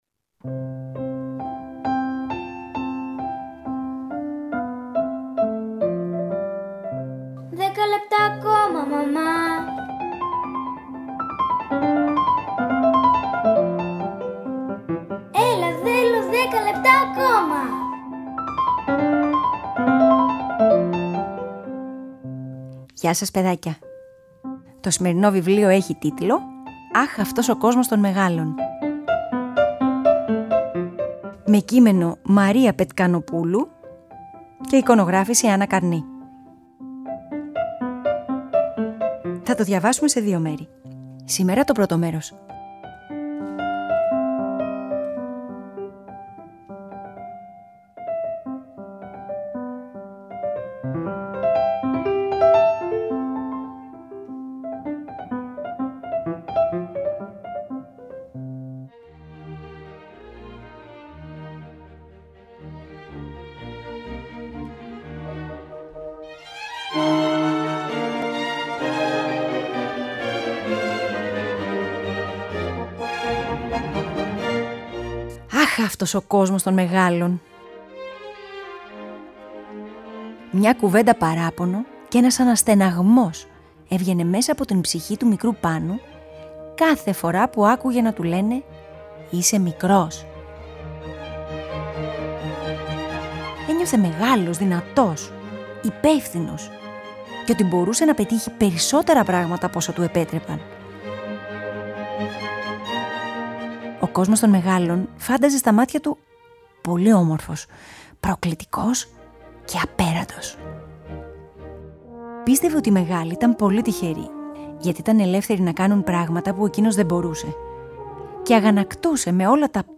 Αφήγηση-Μουσικές επιλογές: